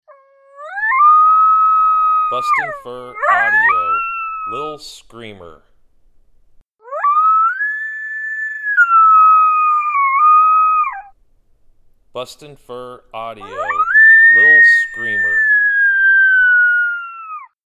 Young Female Coyote howling in response to a lone howl.